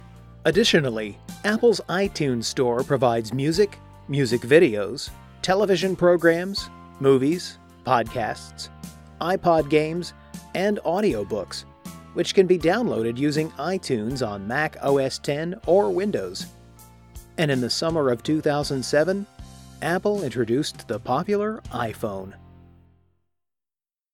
Narration (Apple):
US: General American, Kentucky, Texas